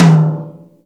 TOM JAZZ TOM.wav